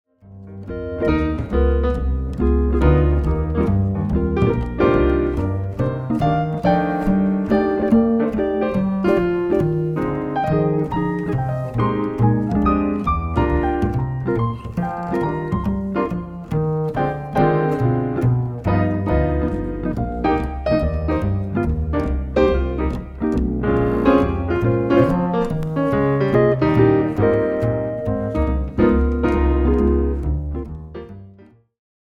A play-along track in the style of Jazz.